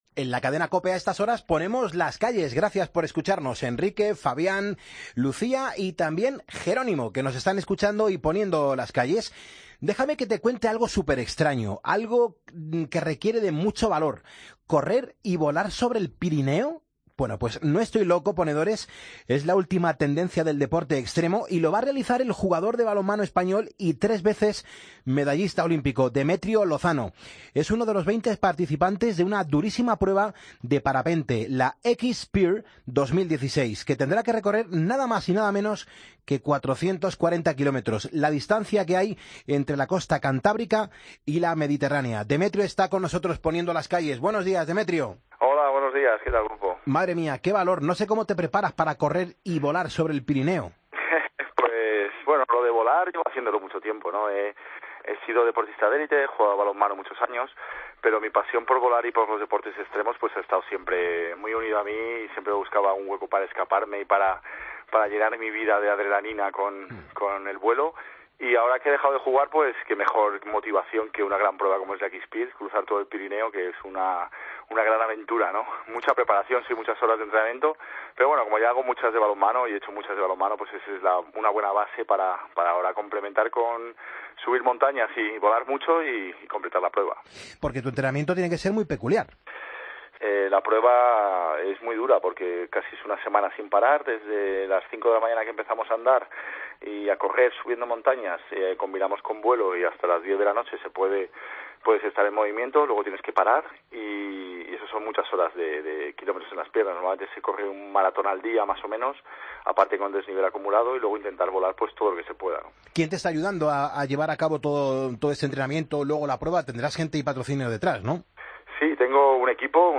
AUDIO: El medallista olímpico, Demetrio Lozano, nos cuenta cómo se preparan para una prueba tan dura: la "X-PYR 2016".